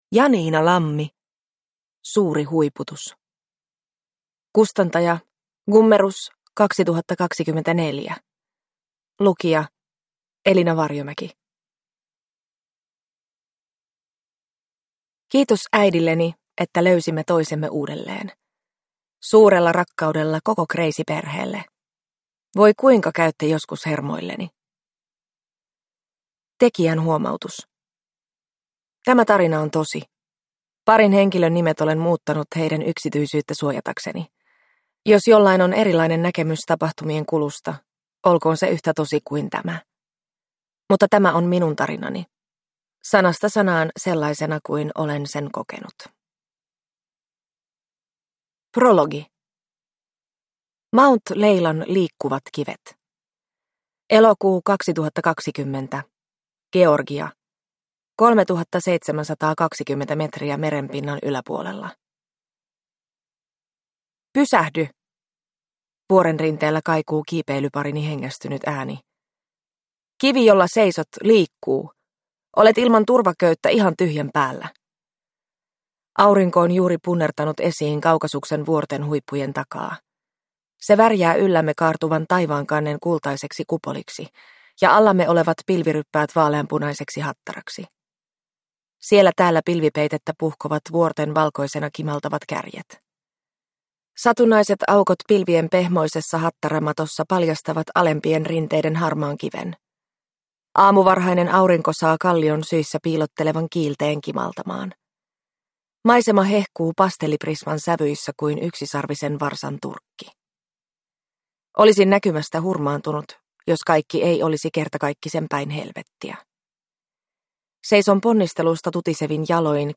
Suuri huiputus – Ljudbok